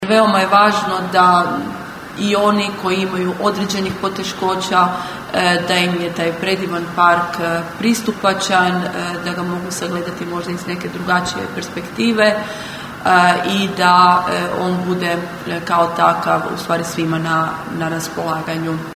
"Park skulptura je dragulj Labinštine", ustvrdila je načelnica Općine Sveta Nedelja Irene Franković: (